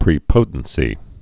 (prē-pōtn-sē)